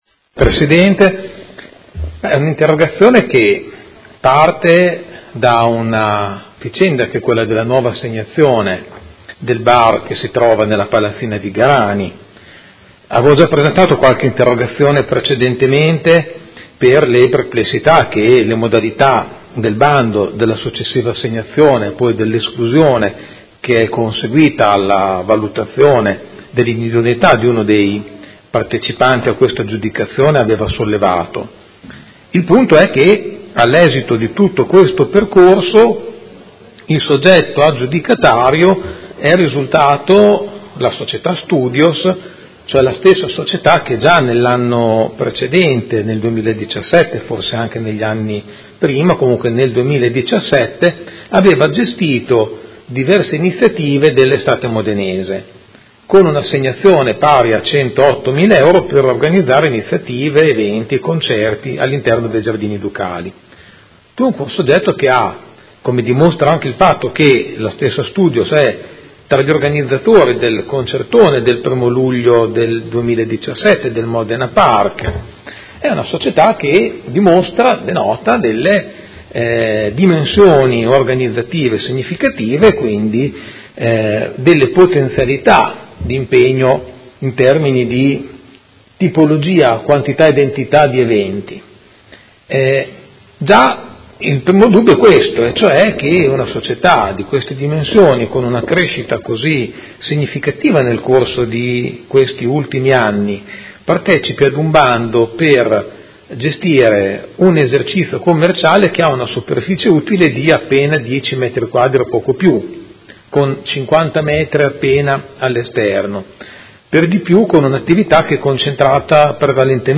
Giuseppe Pellacani — Sito Audio Consiglio Comunale
Seduta del 31/05/2018 Interrogazione del Consigliere Pellacani (Energie per l’Italia) avente per oggetto: L’uso della Palazzina Vigarani, stabile vincolato, all’interno dei Giardini Ducali, parco vincolato, è compatibile con un importante afflusso in grado di alterare la natura del bar di pochi metri oggi presente?